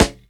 SNARE_DEATH_DO_US_PART.wav